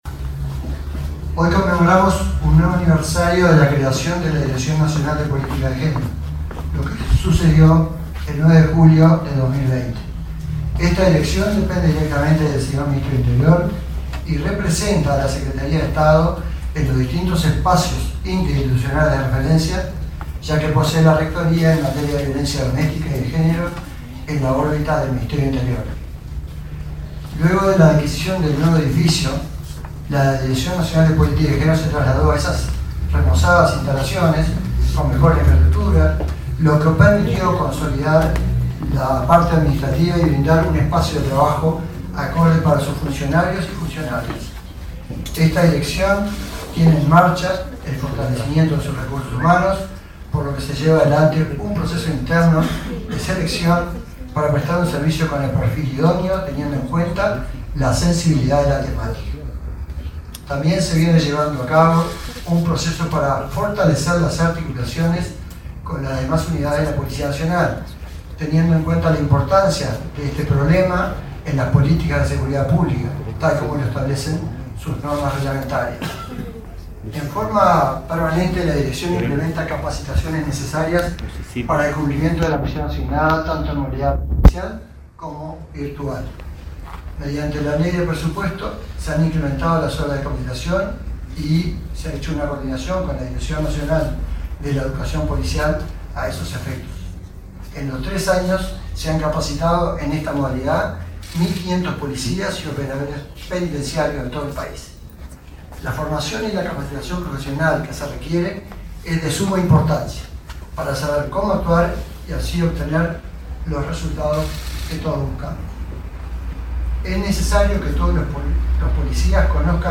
Palabras de autoridades del Ministerio del Interior
El subdirector de la Policía Nacional, Jhonny Diego Sosa, y la directora nacional de Políticas de Género, Angelina Ferreira, participaron en el acto